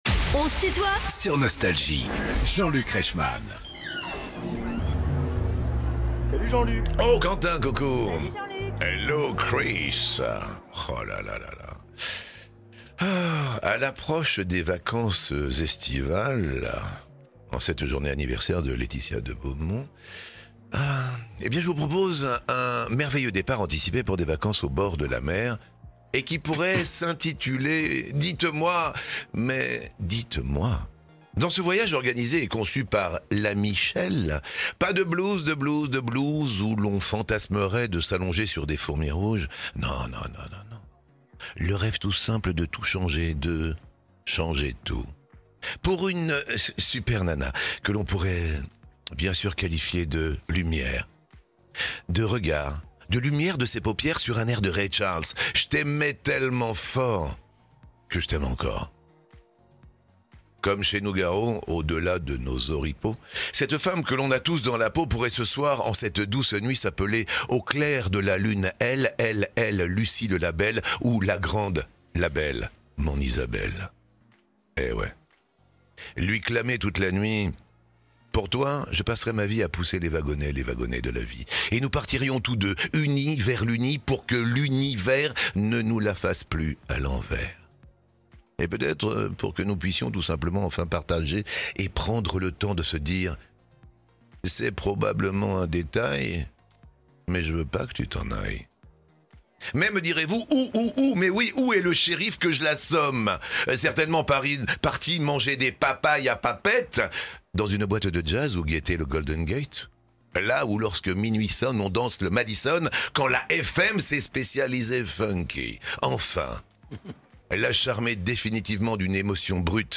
Les interviews
Les plus grands artistes sont en interview sur Nostalgie.